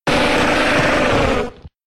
Cri de Gravalanch K.O. dans Pokémon X et Y.